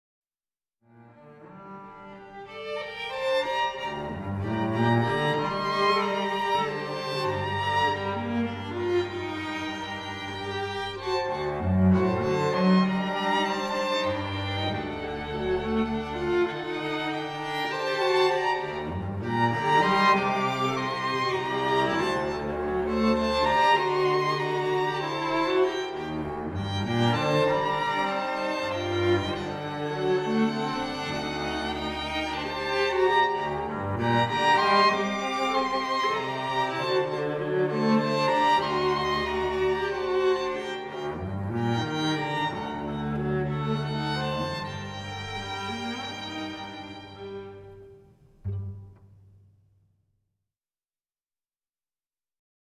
a dreamscape of three Dances for String Quartet.